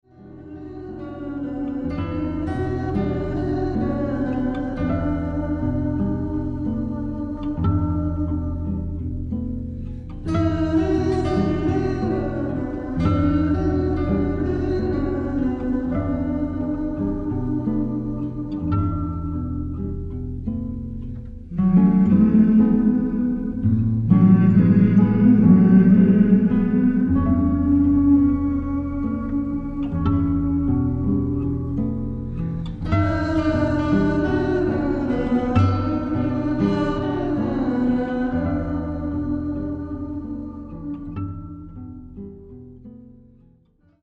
ジャズ、カントリー、ソウルなどを絶妙にブレンドして作られたサウンドはヒップかつクール、とにかく洗練されている。